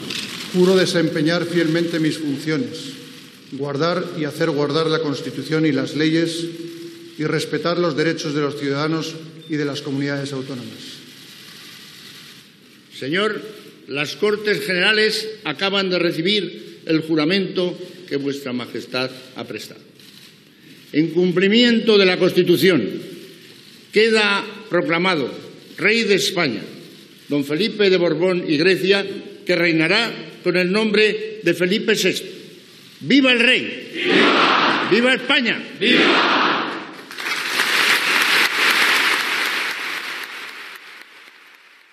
Jurament del rei Felipe VI, davant de les Cortes Generales, el dia de la seva proclamació. El president del Congreso de los Diputados, Jesús Posada, fa la seva proclamació.
Informatiu
El jurament i la proclamació del rei Felipe VI va tenir lloc el dijous 19 de juny, en acte solemne davant de les Cortes Generales.